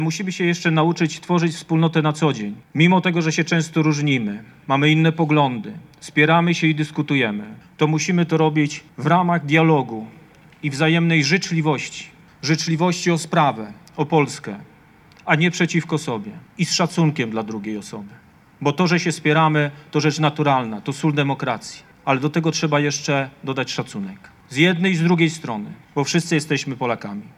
Przedstawiciele miasta, województwa, radni, parlamentarzyści, kombatanci i mieszkańcy Szczecina wzięli dziś udział w obchodach Święta Niepodległości na pl. Szarych Szeregów w Szczecinie.
Wojewoda Zachodniopomorski Zbigniew Bogucki mówił o konieczności tworzenia wspólnoty narodowej, mimo różnic politycznych i światopoglądowych.